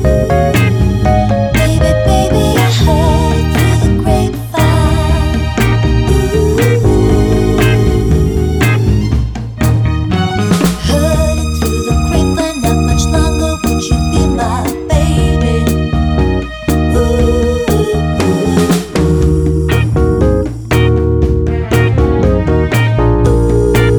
No Backing Vocals Soul / Motown 3:25 Buy £1.50